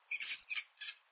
_rat.ogg